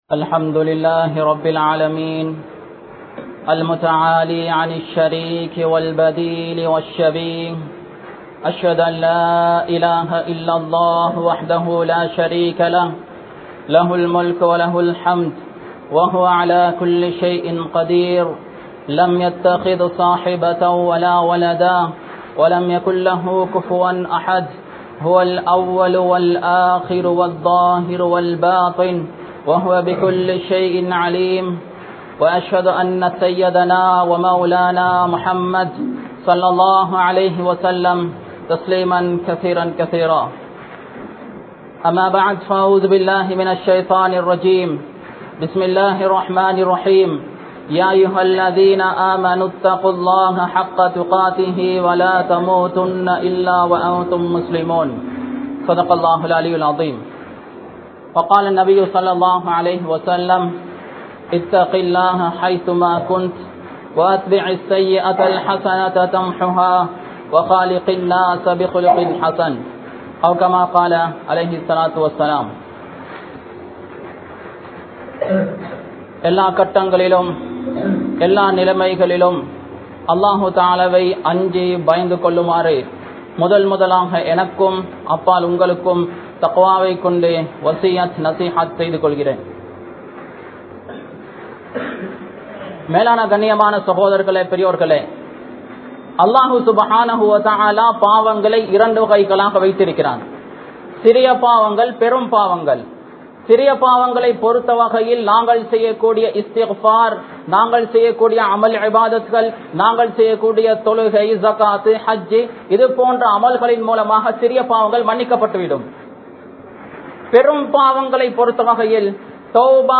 Pettroarai Maranthu Vidatheerhal (பெற்றோரை மறந்து விடாதீர்கள்) | Audio Bayans | All Ceylon Muslim Youth Community | Addalaichenai
Azhar Jumua Masjidh